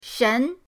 shen2.mp3